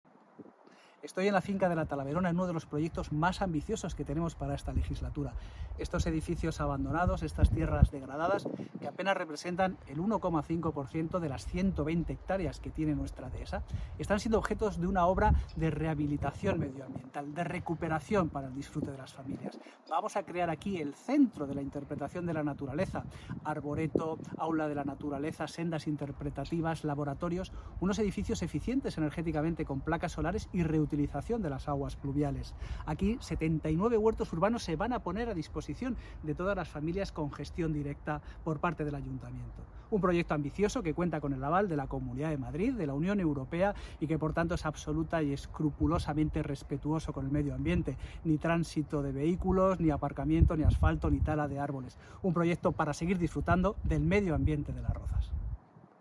Declaraciones del alcalde, José de la Uz.